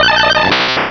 pokeemerald / sound / direct_sound_samples / cries / dugtrio.aif
dugtrio.aif